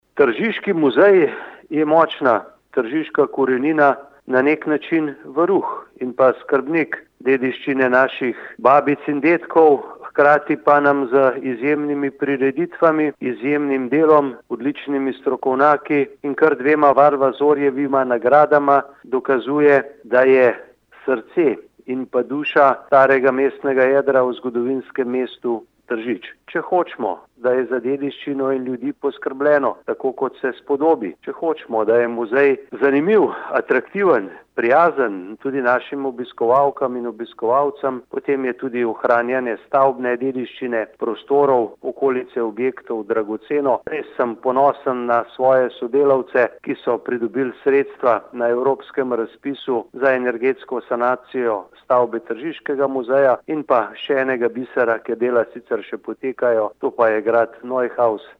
izjava_mag.borutsajoviczupanobcinetrzic.mp3 (1,4MB)